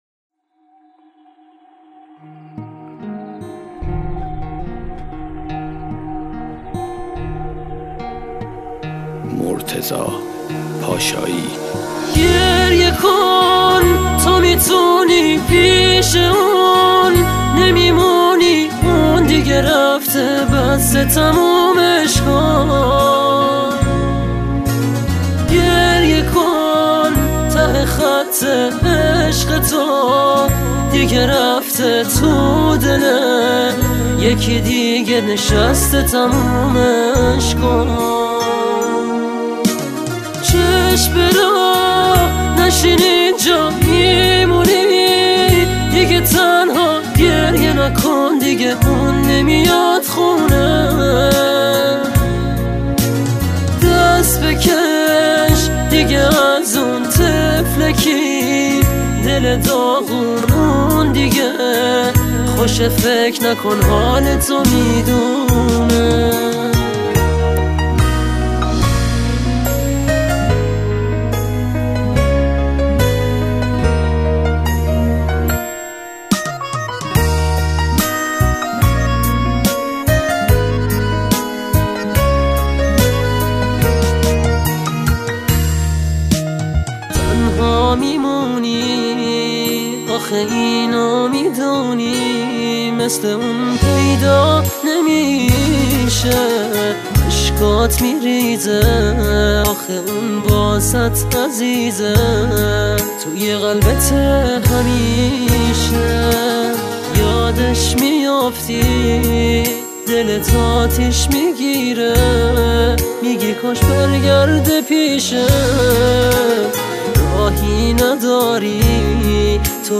آهنگ گریه آور غمگین
آهنگ غمگین